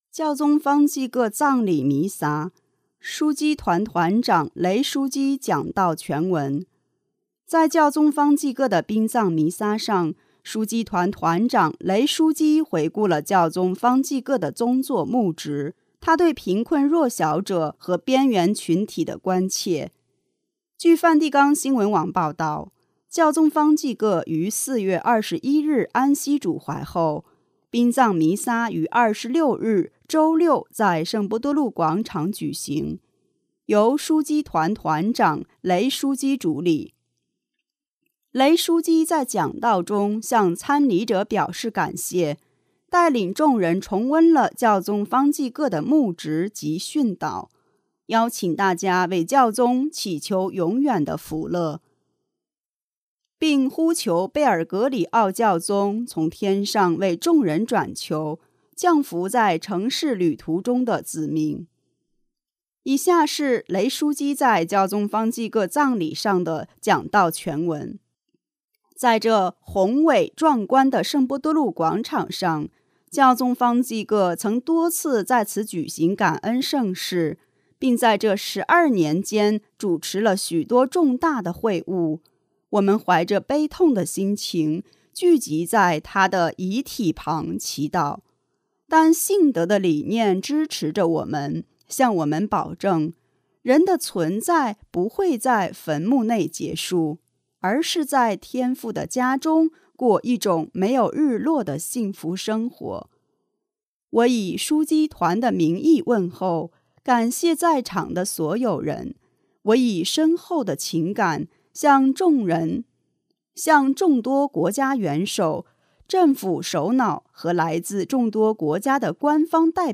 据梵蒂冈新闻网报道，教宗方济各于4月21日安息主怀后，殡葬弥撒于26日周六在圣伯多禄广场举行，由枢机团团长雷（Giovanni Battista Re）枢机主礼。